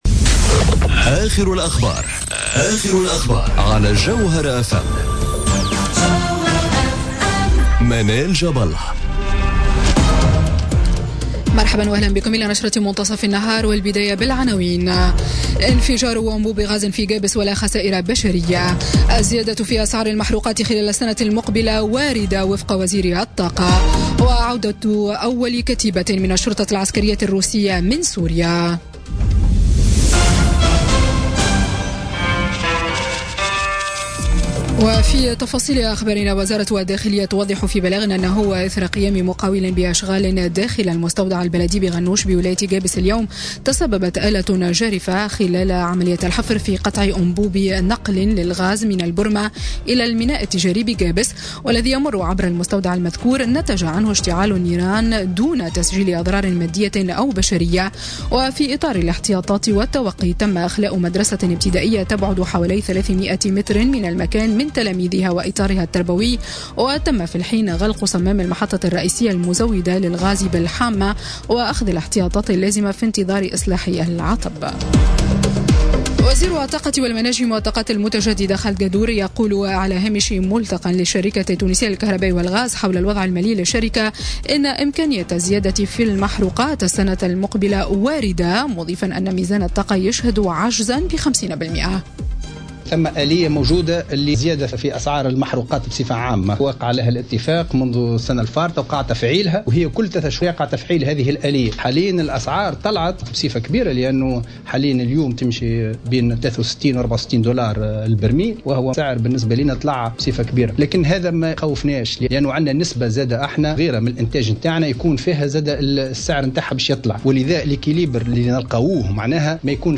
نشرة أخبار منتصف النهار ليوم الثلاثاء 12 ديسمبر 2017